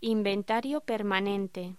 Locución: Inventario permanente
voz